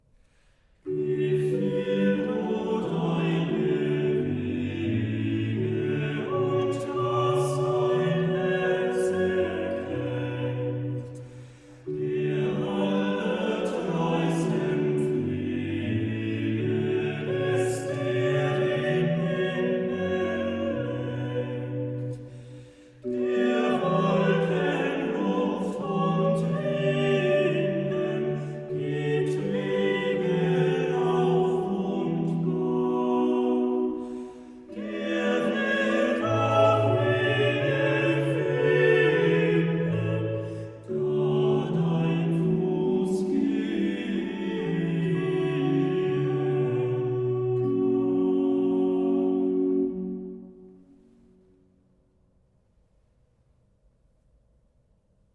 Uitgevoerd door de Augsburger Domsingknaben (Youtube)